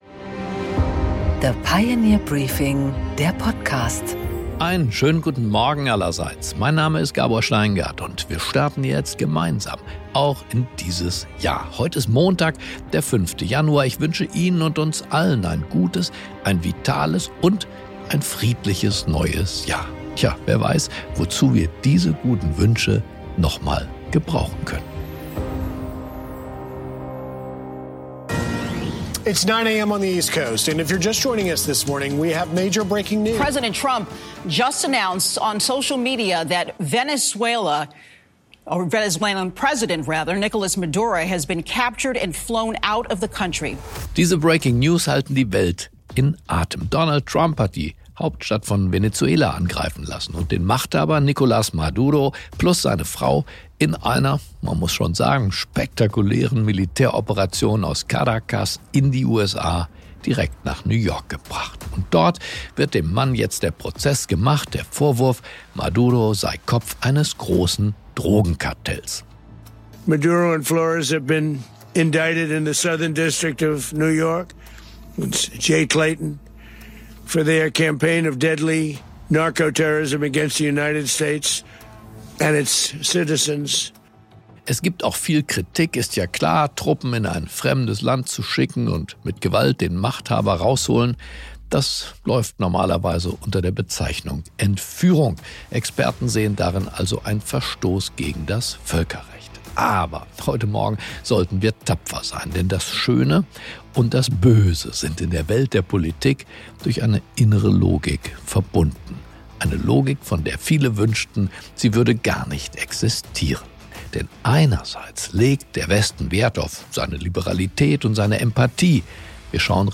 Gabor Steingart präsentiert das Pioneer Briefing.